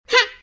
yoshi_Jump_Take1.ogg